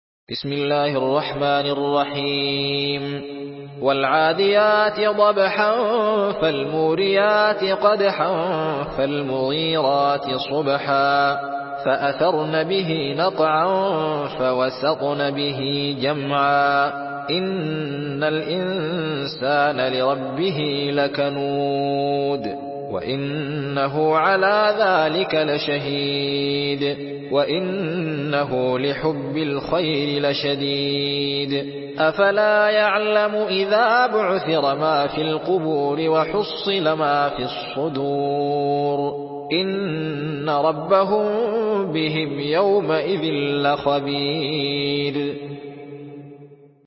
سورة العاديات MP3 بصوت الزين محمد أحمد برواية حفص
مرتل